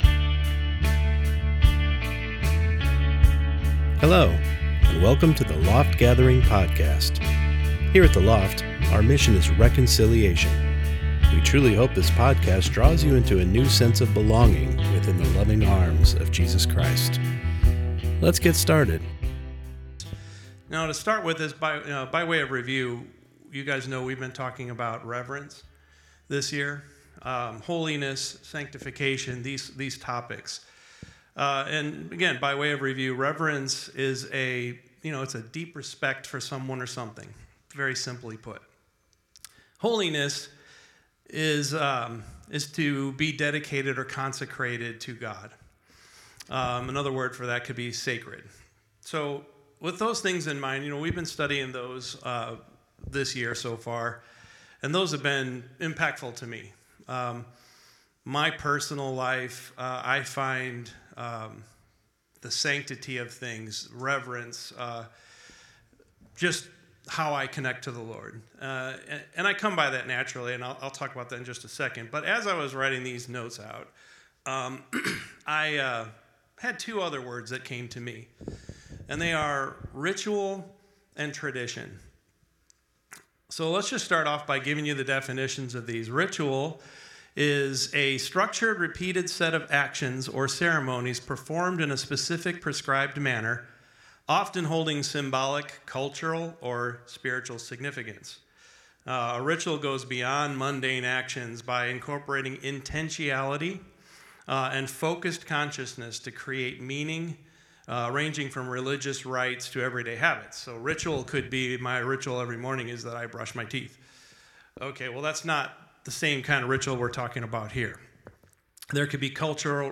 Service: Sunday Morning Service